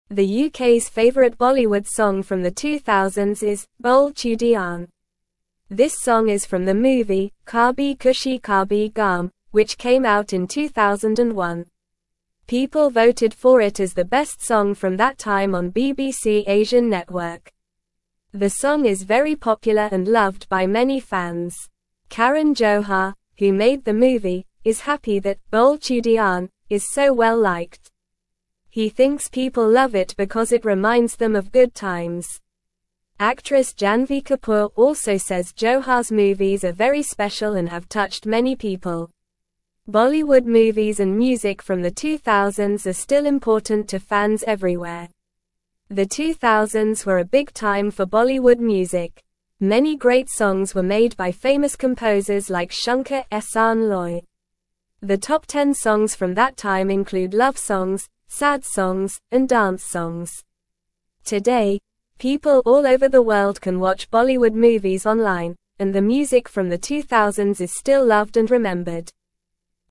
Normal
English-Newsroom-Lower-Intermediate-NORMAL-Reading-Bole-Chudiyan-A-Popular-Bollywood-Song.mp3